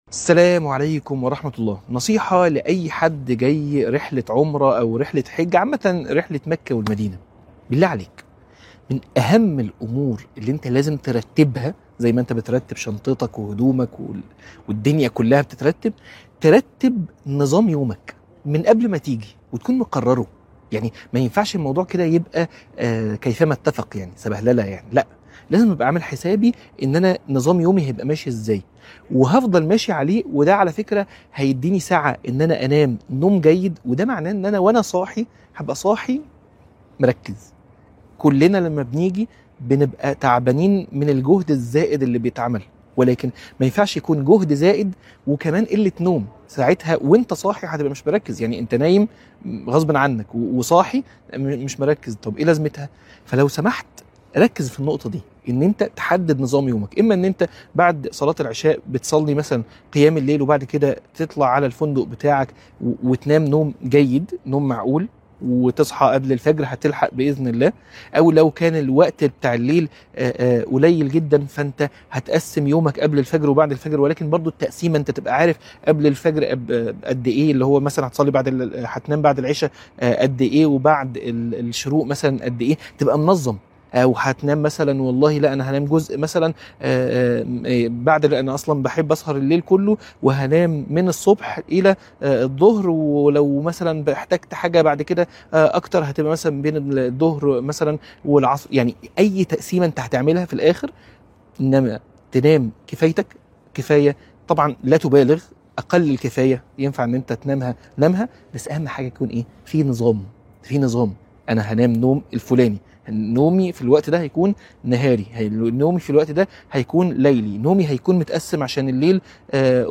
عنوان المادة نصيحة لأي حد جاي عمرة أو حج - من الحرم